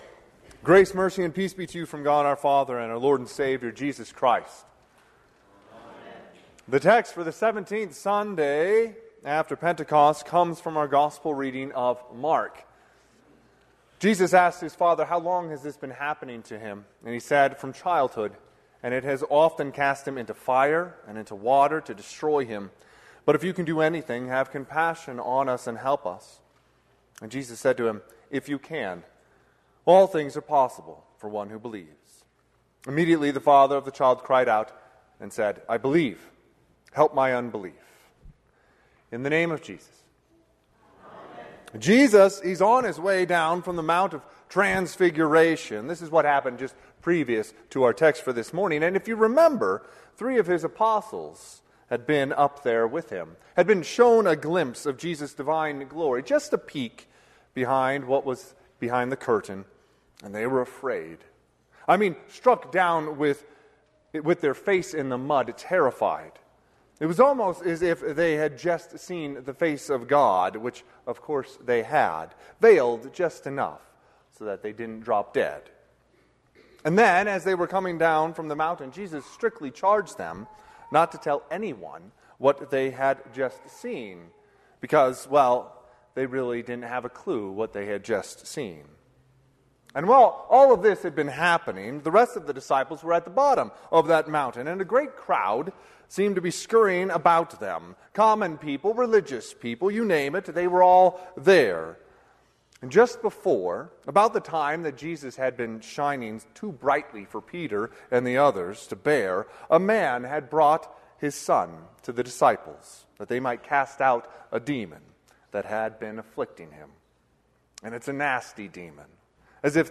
Sermon - 9/15/2024 - Wheat Ridge Lutheran Church, Wheat Ridge, Colorado
Seventeenth Sunday after Pentecost